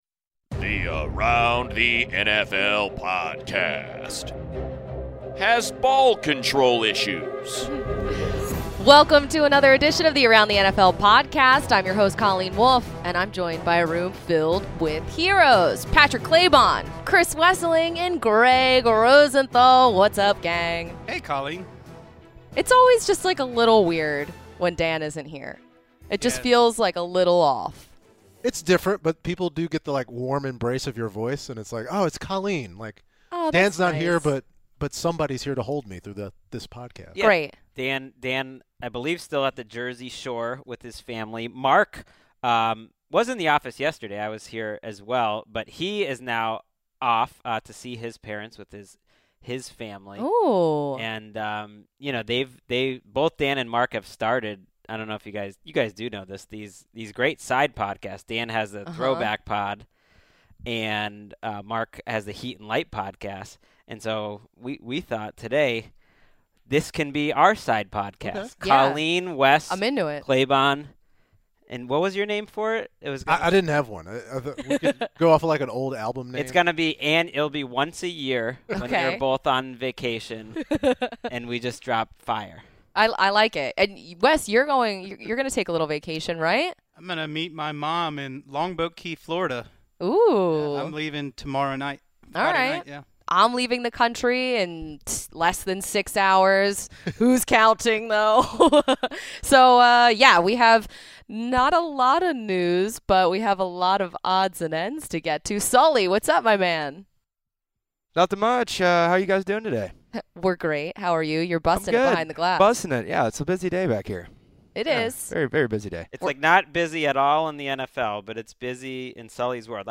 The heroes then play a new game show where they predict which NFL QBs will lose their job and by what holiday during the season.